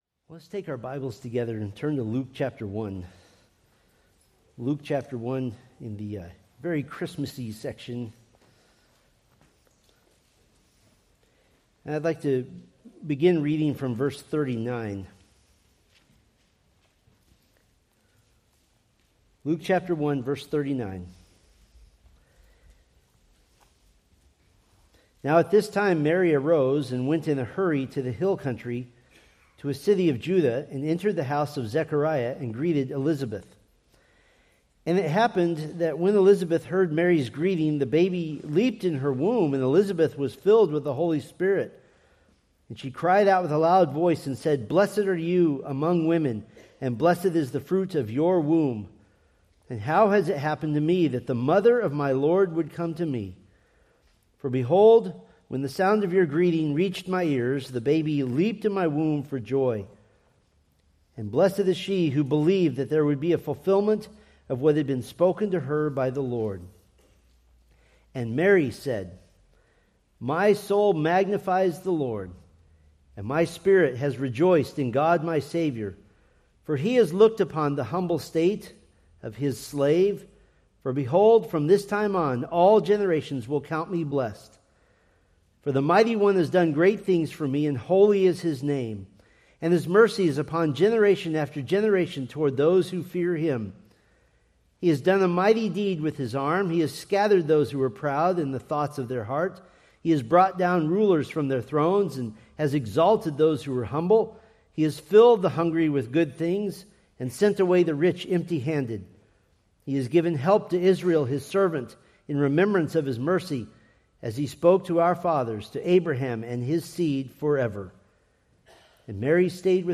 Preached November 30, 2025 from Luke 1:46-47